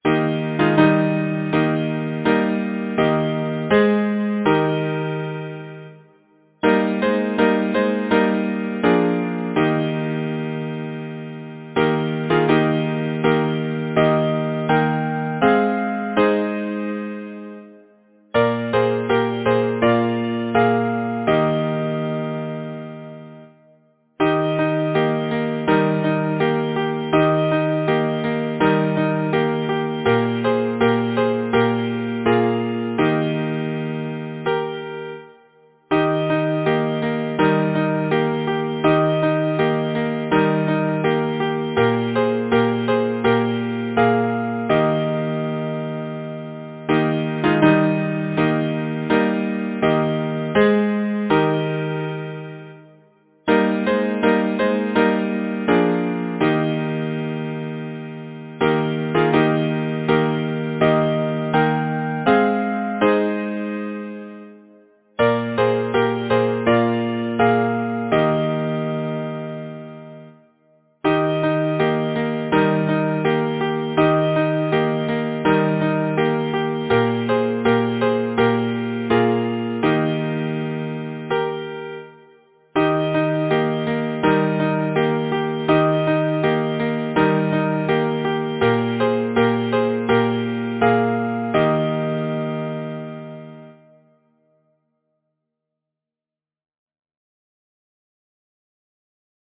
Title: Evening chimes Composer: Charles Lauren Moore Lyricist: Number of voices: 4vv Voicing: SATB Genre: Secular, Partsong
Language: English Instruments: A cappella